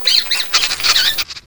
sniff.wav